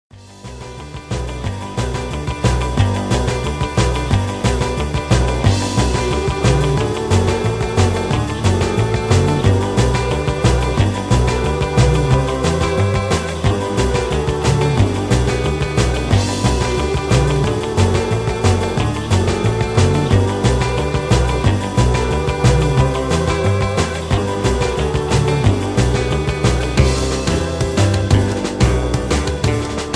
backing tracks
rock and roll